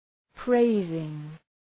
Προφορά
{‘preızıŋ}